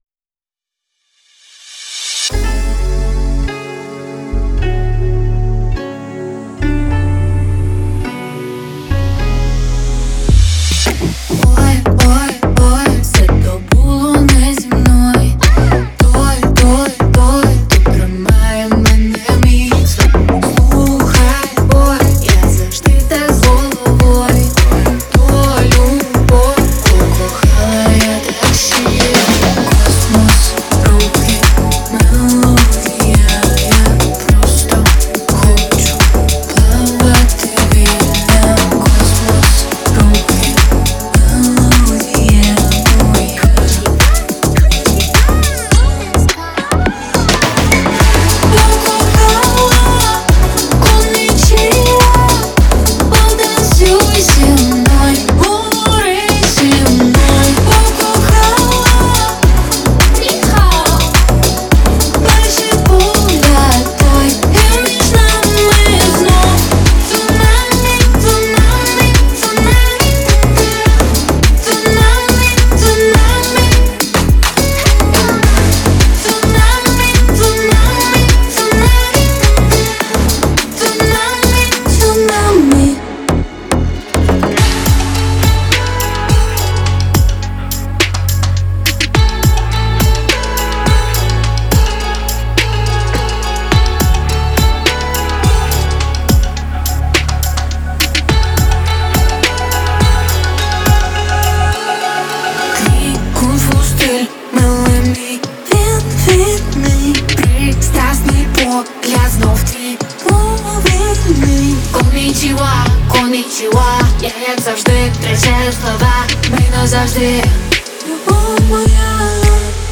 За счет динамичного бита и мелодичных синтезаторов
делая его идеальным для танцевальных вечеринок.